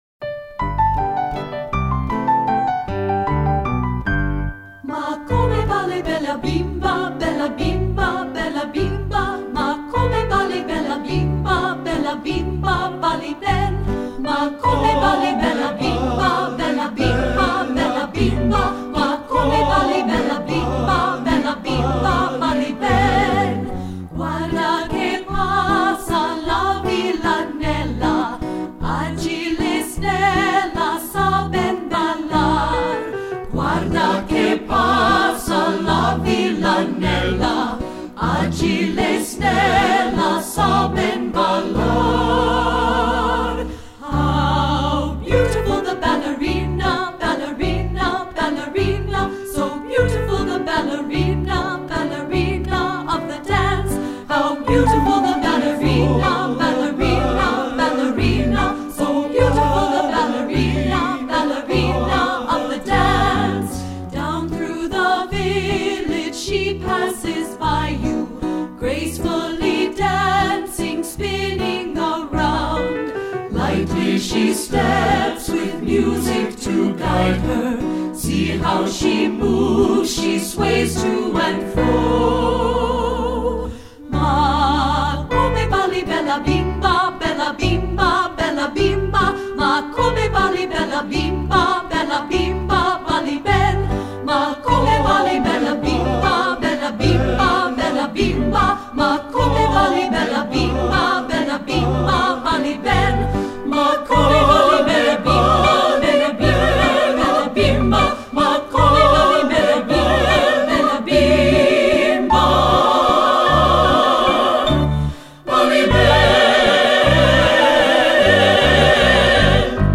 Composer: Italian Folk Song
Voicing: 3-Part Mixed